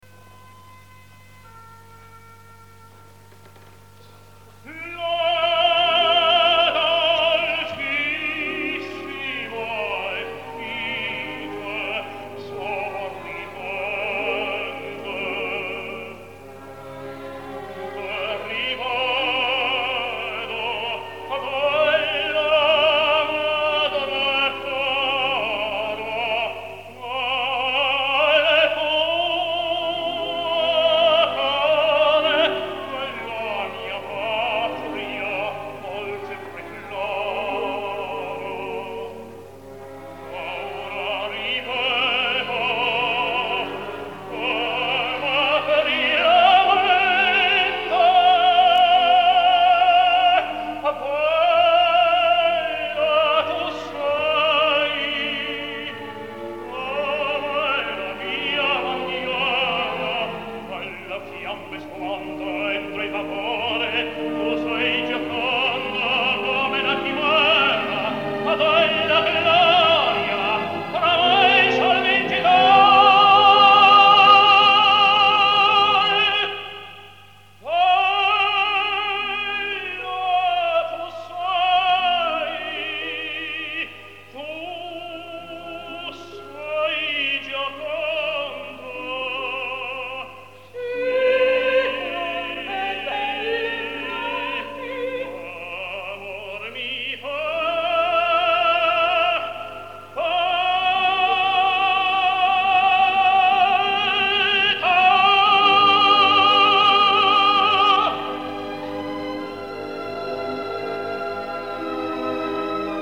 Великолепный тенор!